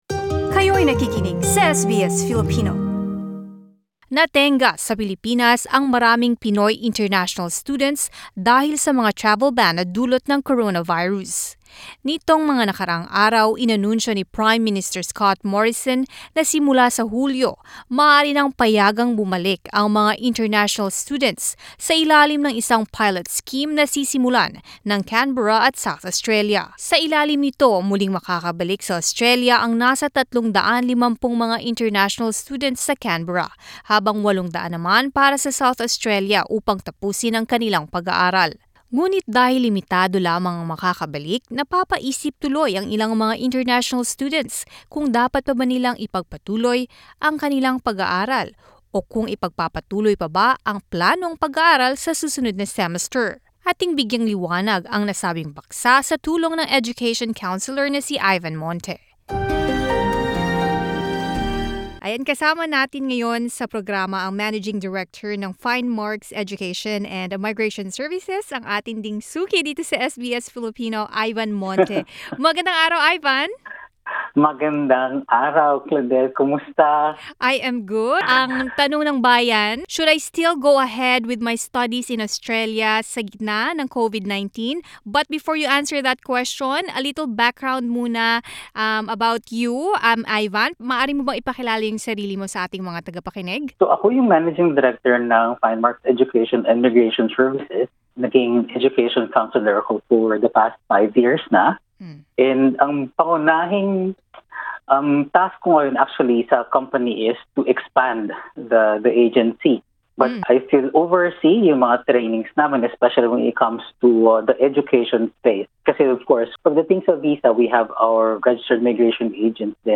In an interview with SBS Filipino